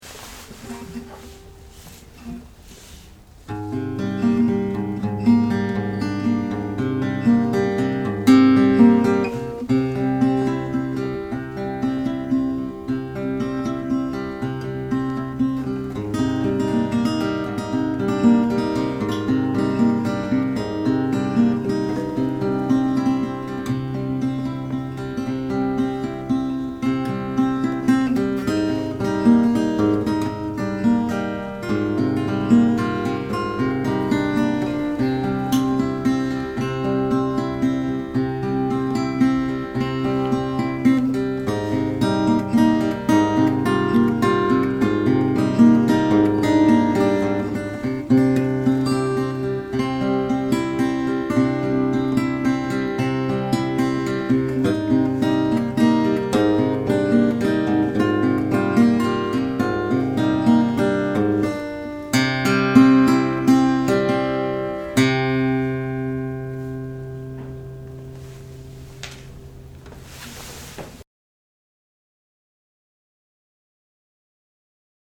Tags: music folk pop original songs